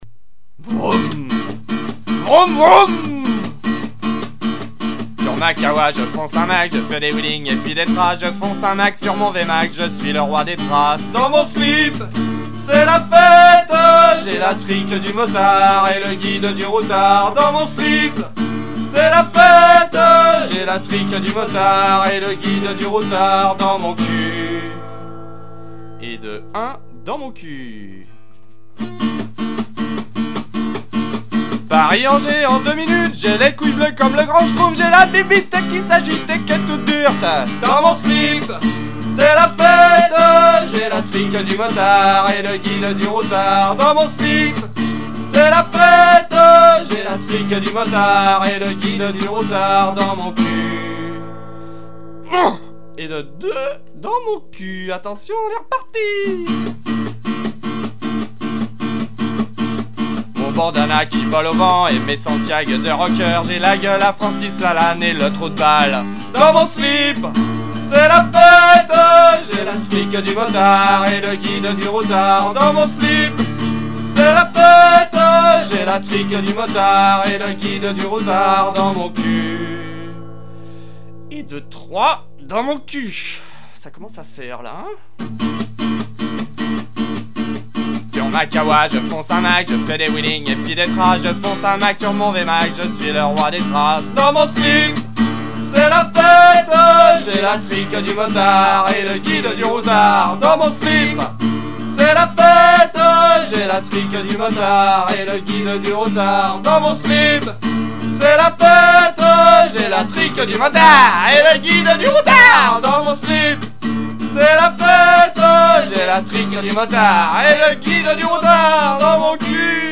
Le guide du routard Chanson amusante...